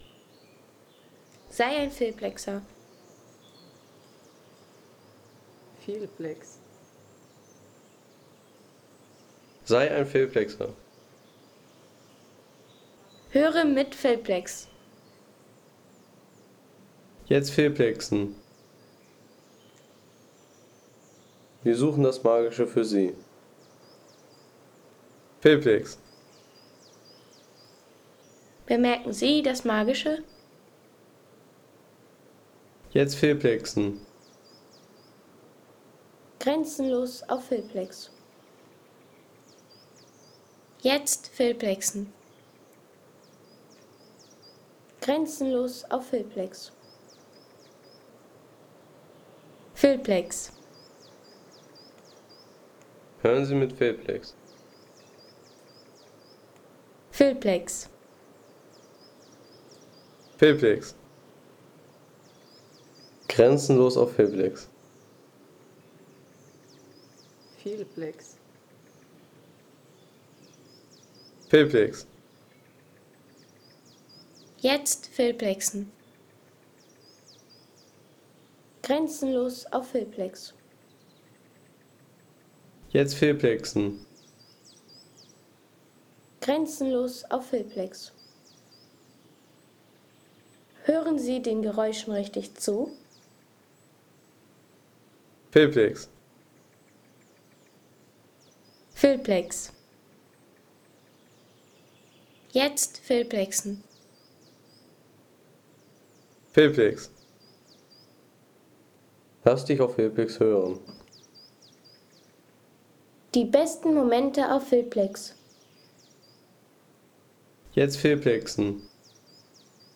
Waldgeräusche der Sächsischen Schweiz
Die Waldgeräusche im Nationalpark der Sächsischen Schweiz.